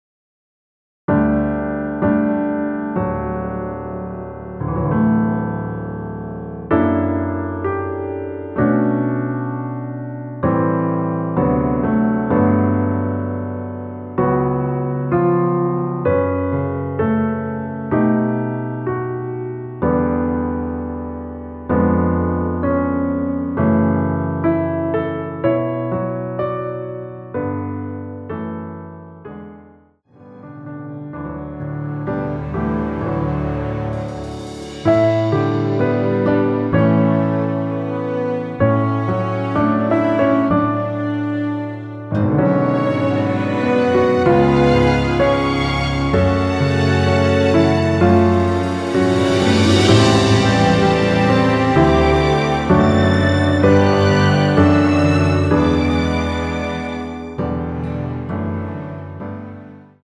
MR입니다.
전주 없이 시작하는 곡이라 노래하기 편하게 전주 1마디 만들어 놓았습니다.(미리듣기 확인)
원키에서(-4)내린 MR입니다.
앞부분30초, 뒷부분30초씩 편집해서 올려 드리고 있습니다.